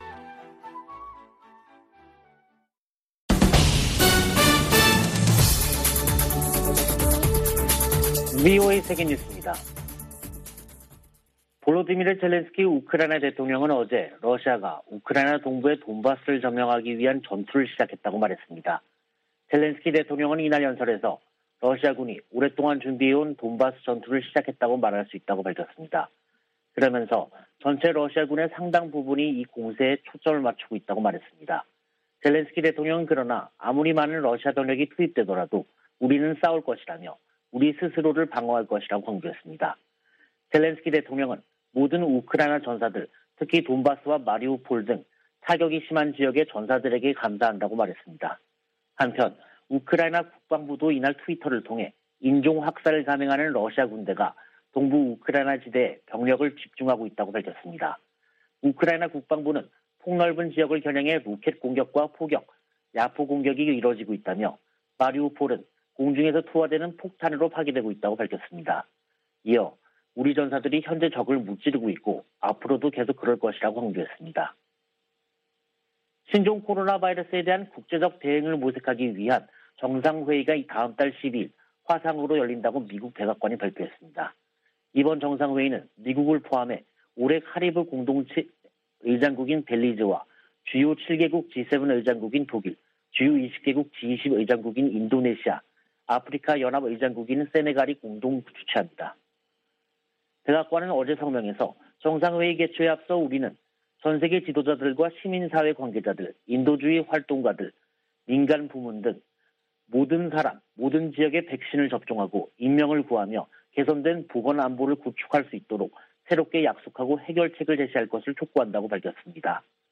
VOA 한국어 간판 뉴스 프로그램 '뉴스 투데이', 2022년 4월 19일 3부 방송입니다. 미 국무부는 북한이 도발을 계속하면 상응 조치를 이어갈 것이라고 경고했습니다. 미 국방부는 북한이 신형 전술유도무기를 시험 발사했다고 밝힌 데 관한 정확한 평가를 위해 분석 중이라고 밝혔습니다. 유엔은 신형 전술유도무기를 시험했다는 북한의 주장에 대해 긴장을 고조시킬 뿐이라고 비판했습니다.